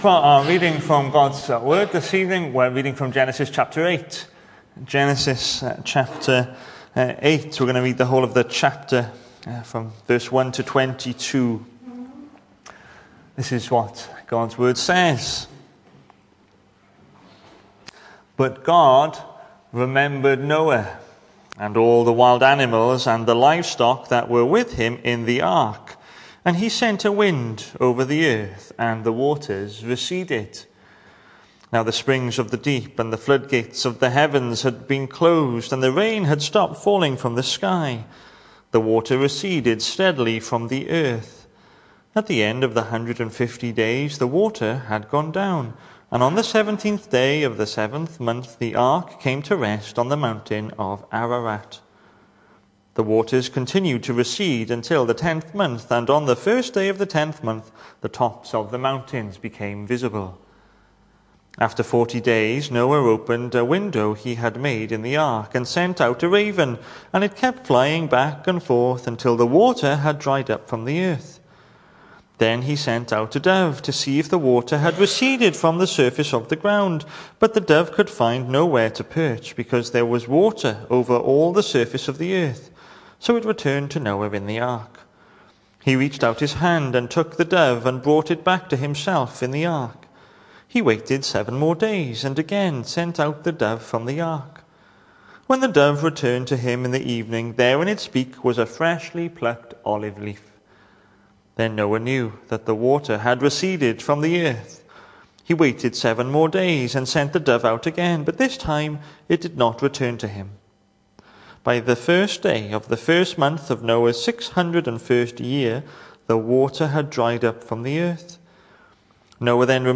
Hello and welcome to Bethel Evangelical Church in Gorseinon and thank you for checking out this weeks sermon recordings.
The 23rd of March saw us hold our evening service from the building, with a livestream available via Facebook.